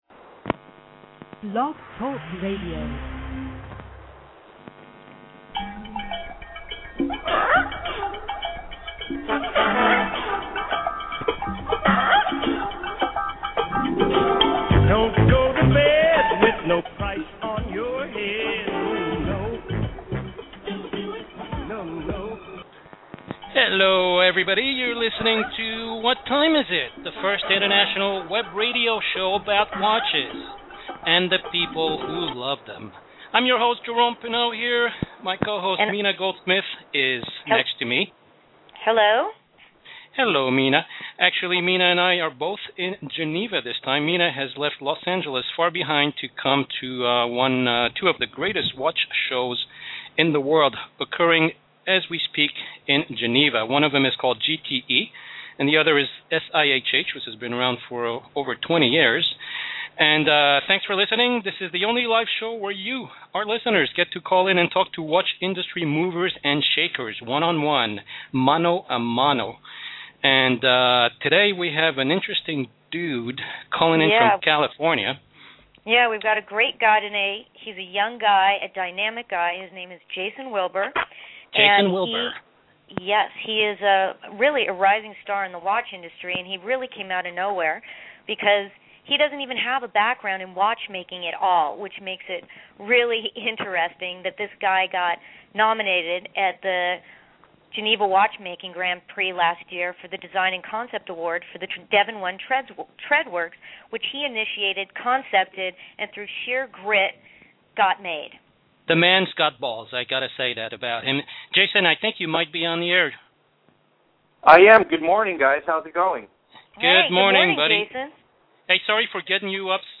“What Time Is It?” is the first live international web radio show about watches and the people who love them!
You can also call in and participate via phone or Skype.